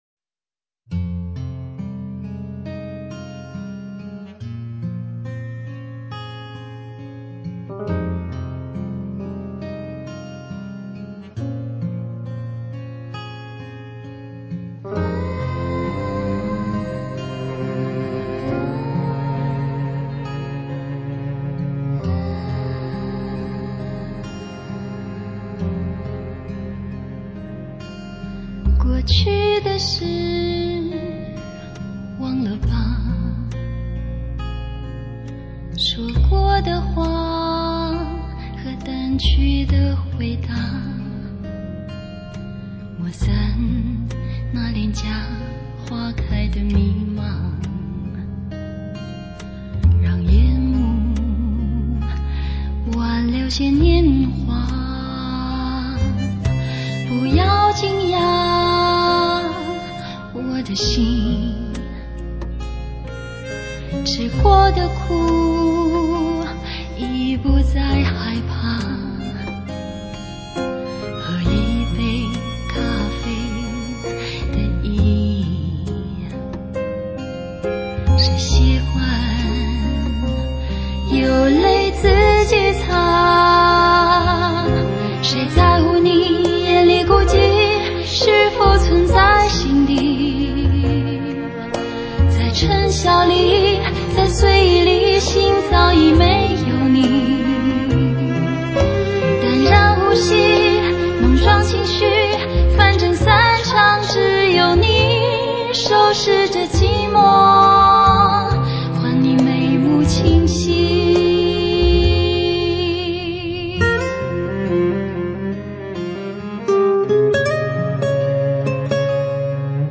华语乐坛最纯净的女声
所有歌曲的旋律都十分柔和
伴奏采用真乐器